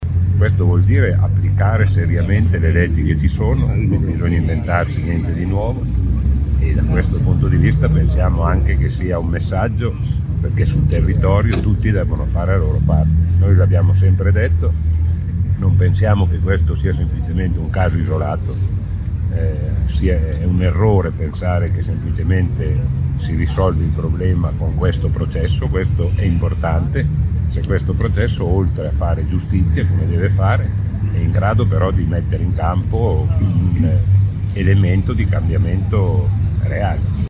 Davanti al Tribunale di Latina, il segretario generale della Cgil, Maurizio Landini, ha ribadito l’impegno del sindacato contro lo sfruttamento.